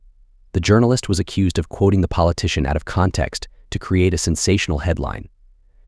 Play.ht-The-journalist-was-accused-of-quoting-the.wav